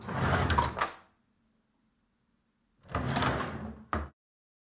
打开木抽屉声音
标签： 抽屉 打开
声道立体声